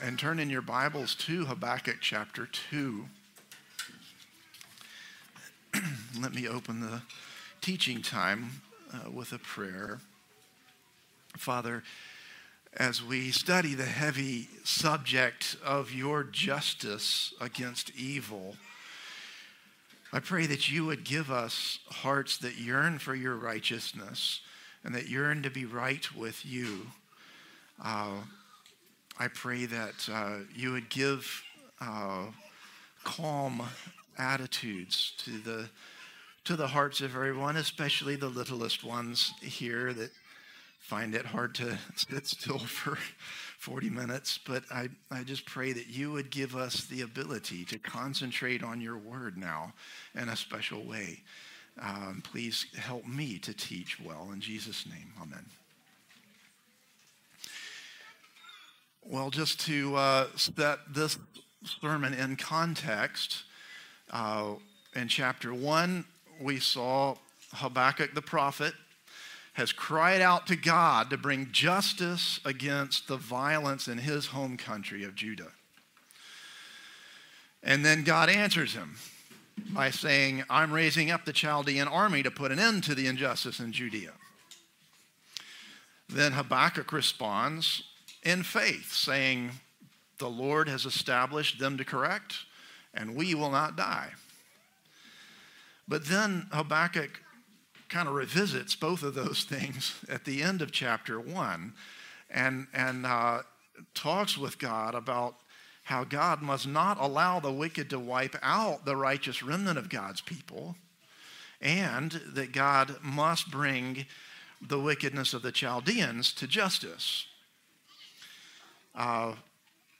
Christ the Redeemer Church | Sermon Categories Judgment Day